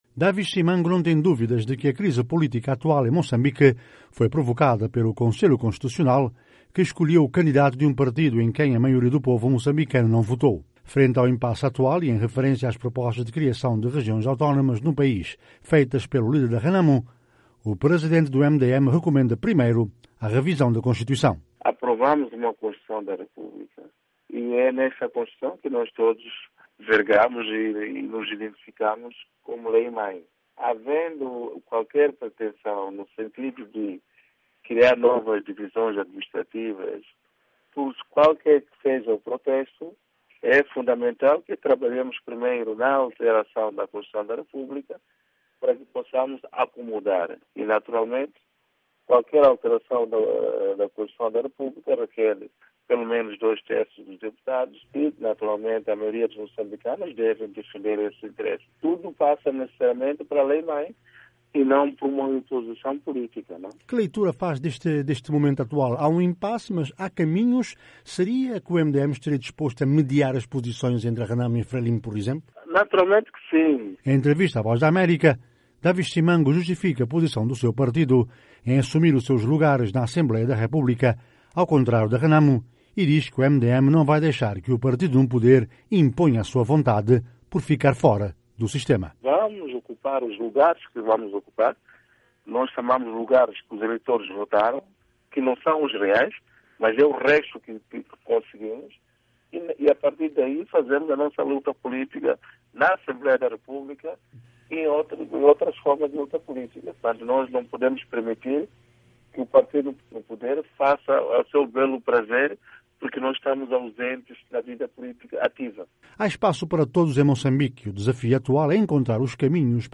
Em entrevista à VOA, Daviz Simango critica a partidarização do Estado, defende a actuação do seu partido dentro do marco constitucional e aponta o dedo ao enriquecimento de alguns que se beneficiam dos recursos do país.